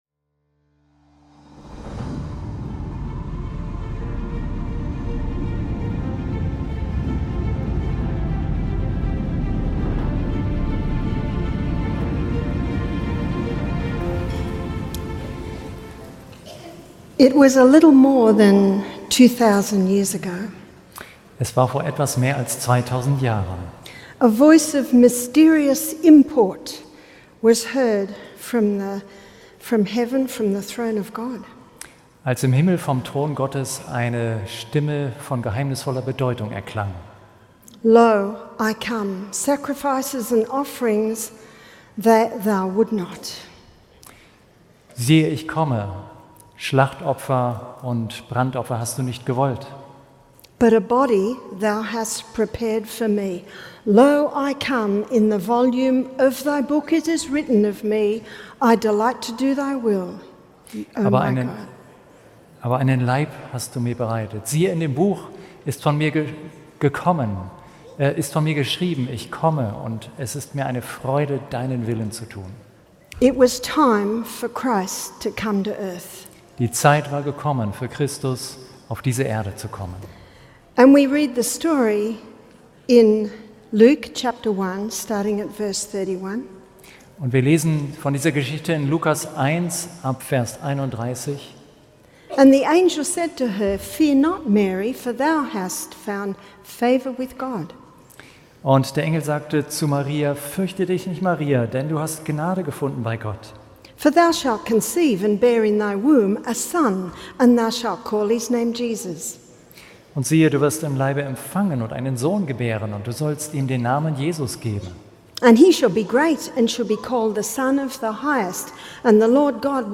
Entdecken Sie die faszinierenden Parallelen zwischen biblischen Prophezeiungen und heute. Der Vortrag beleuchtet, wie die Entscheidung eines einzelnen wie Pilatus weitreichende Konsequenzen für die Menschheit hatte.